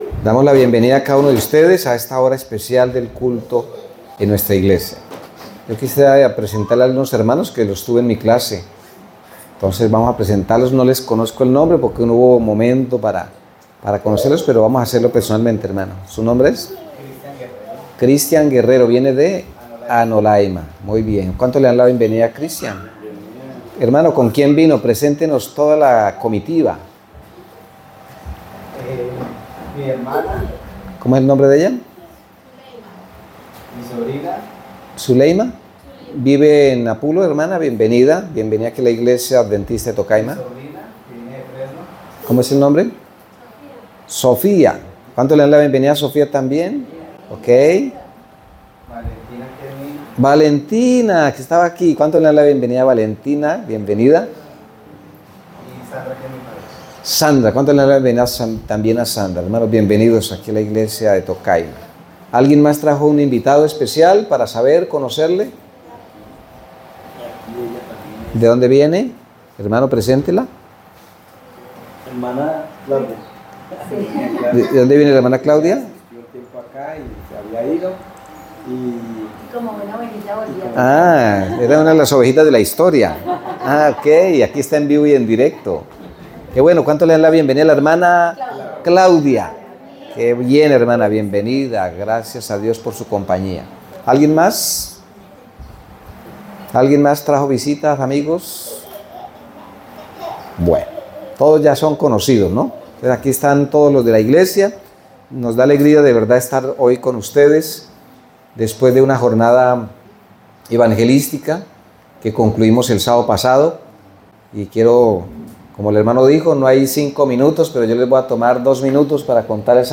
Predicaciones Primer Semestre 2025